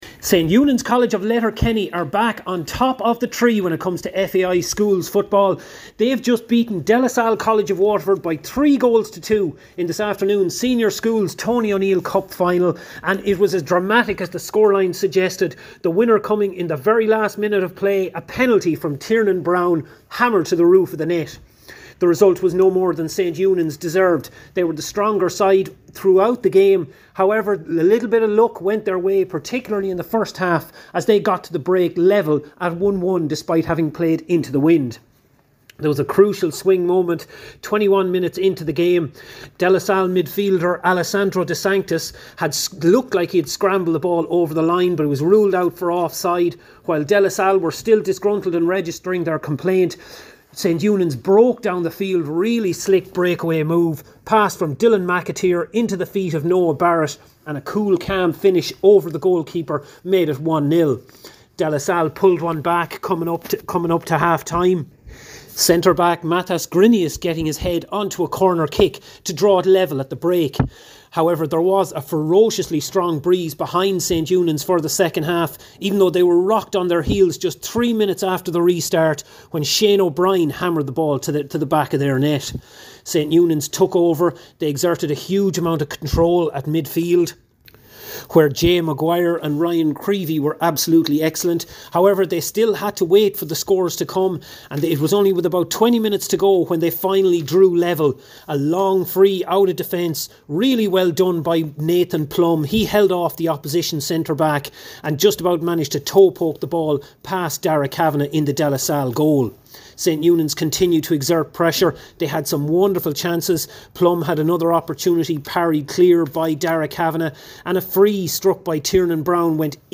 FT Report: St Eunan’s College win National Senior Schools Cup